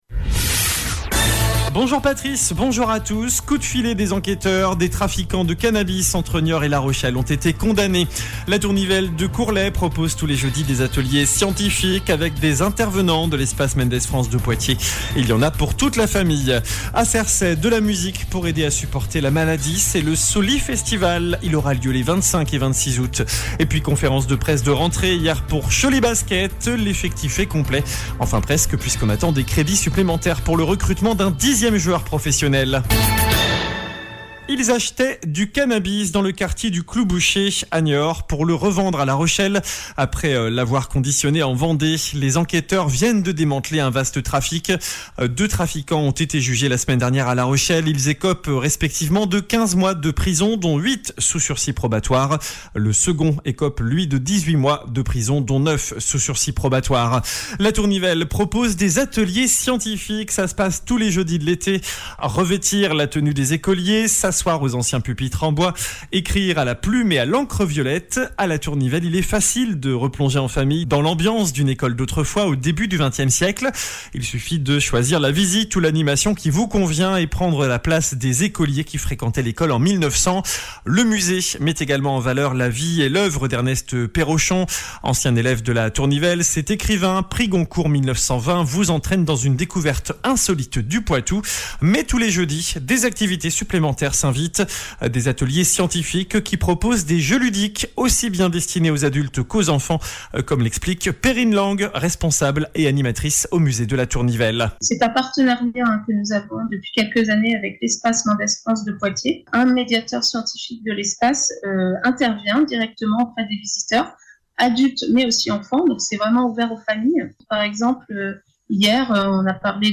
JOURNAL DU MARDI 08 AOÛT ( MIDI )